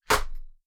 toasterstep1.wav